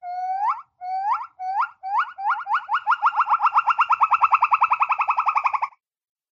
monkey-around_14202.mp3